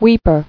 [weep·er]